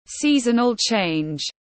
Giao mùa tiếng anh gọi là seasonal change, phiên âm tiếng anh đọc là /ˈsizənəl ʧeɪnʤ/